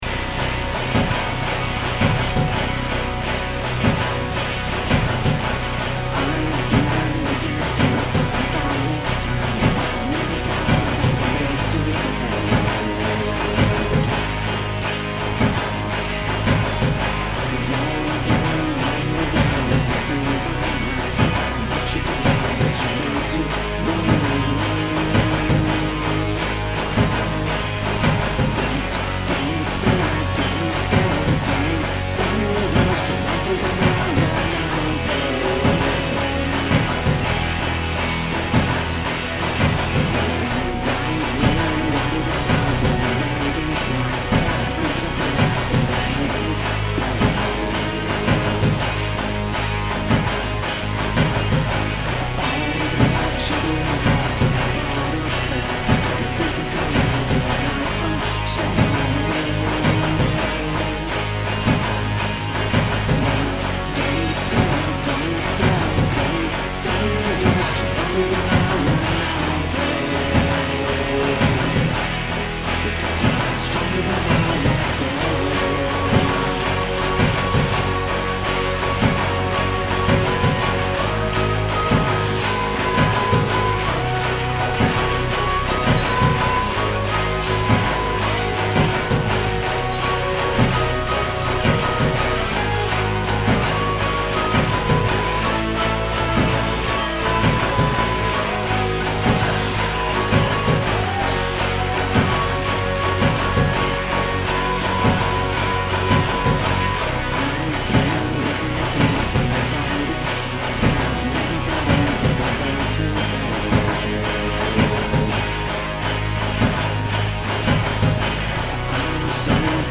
In any event, I ended up adding some distorted guitars to this one.
Like the melody, but just can't hear the vocals.
I enjoy the layering on this one - the drum part is awkward, but you make it work for the song.
I sort of dig the dirty mix you did there.
The almost mechanical rhythm track goes well with the shoegazer-ish layers of thick guitars.
Yes - it's sung in octaves throughout.